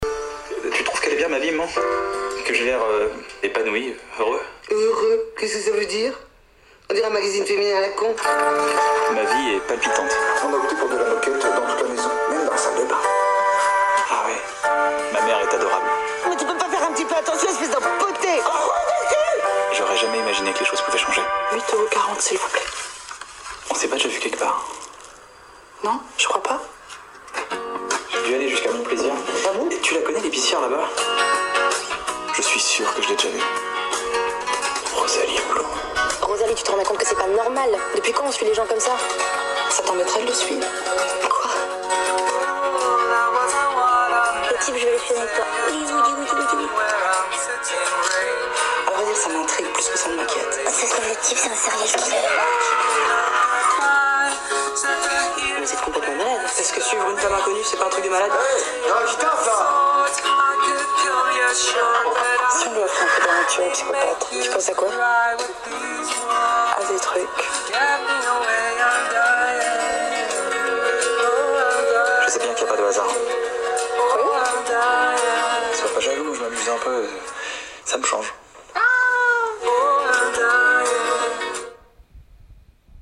Rosalie Blum-bande annonce
Mais nous faisons ça tout le temps oralement.
2. maman : il prononce M’man.
rosalie-blum-bande-annonce.mp3